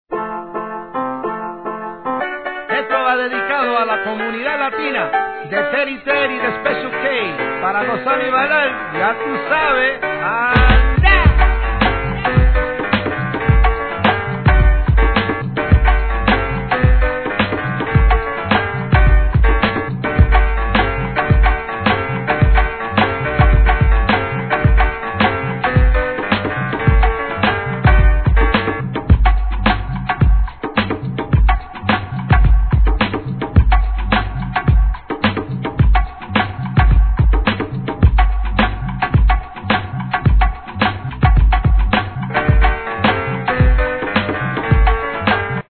HIP HOP/R&B
ブレイクビーツ仕様!